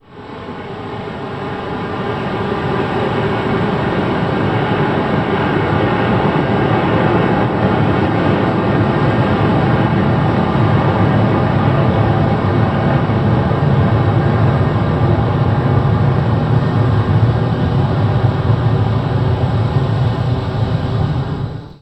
Avión volando en circulo a 200 metros de altura